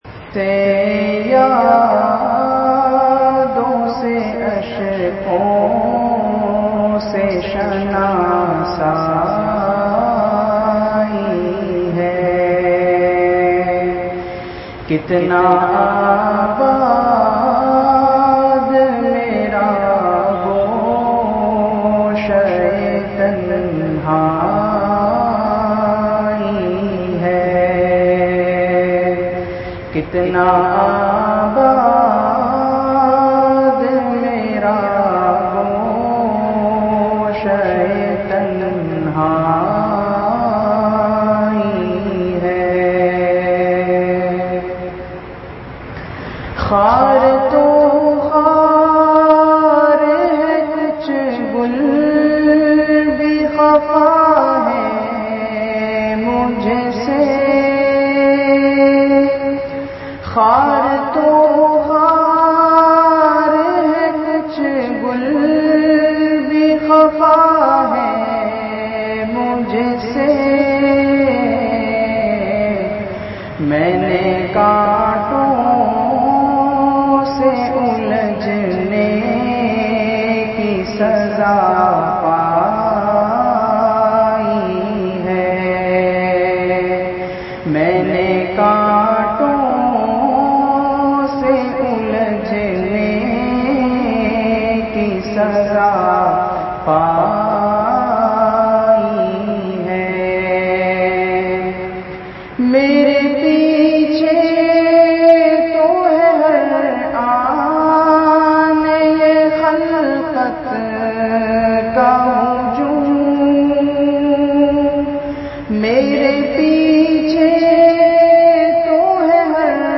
Bayanat
Bivion ke sath acha slook kren (bad asar jama masjid bilal phase 01 airport rod kohsar )